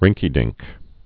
(rĭngkē-dĭngk) Slang